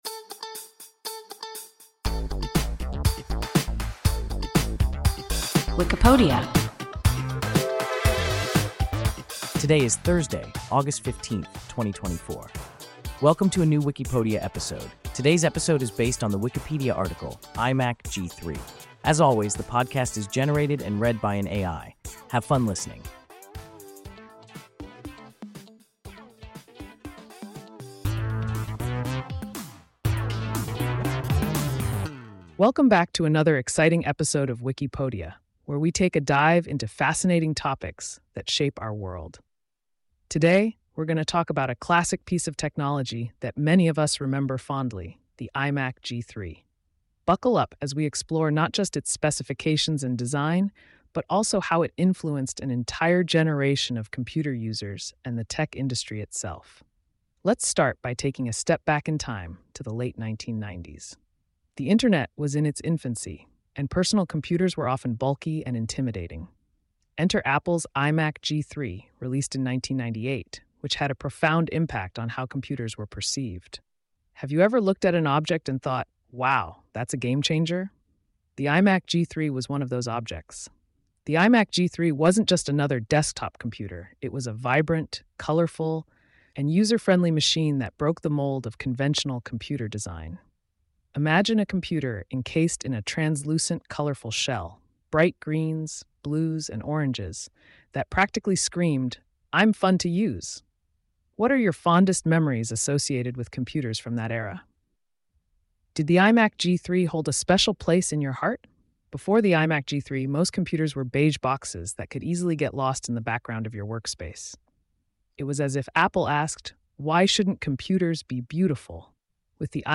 IMac G3 – WIKIPODIA – ein KI Podcast